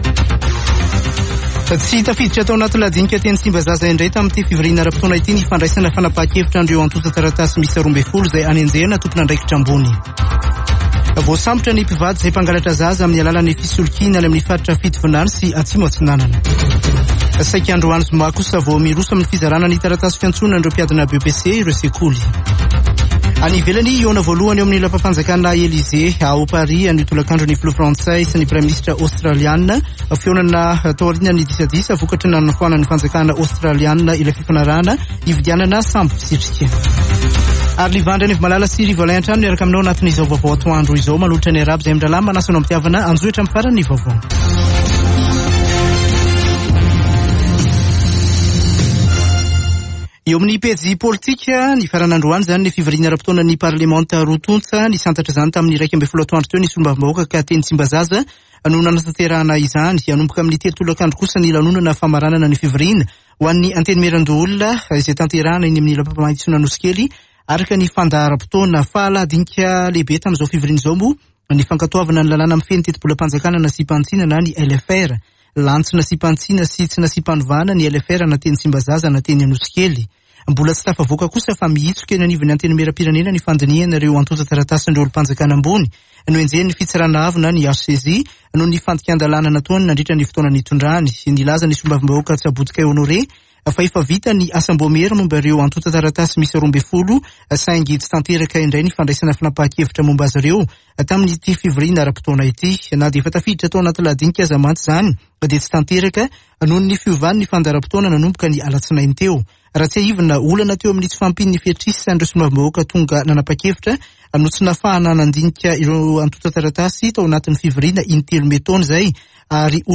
[Vaovao antoandro] Zoma 1 jolay 2022